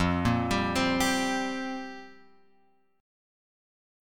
Fsus2sus4 chord {1 1 x 0 1 3} chord